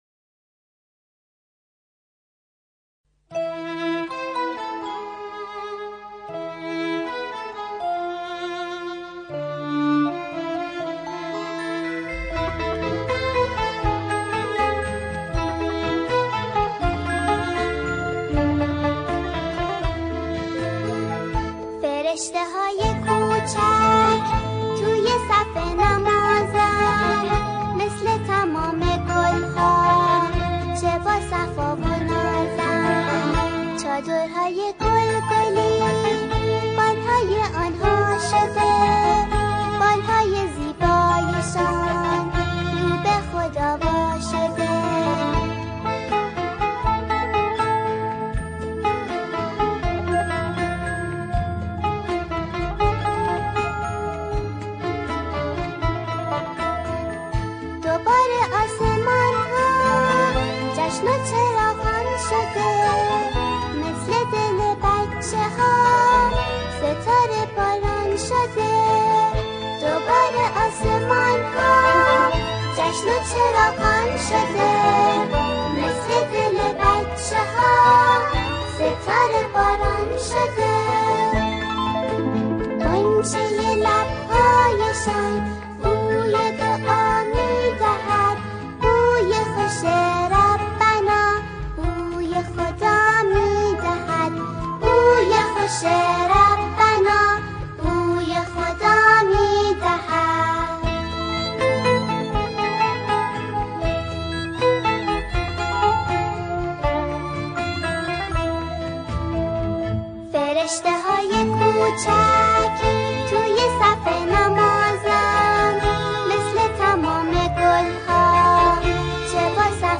سرود کودکانه
با صدای دخترانه